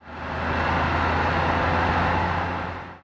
Highway / oldcar / tovertake2.ogg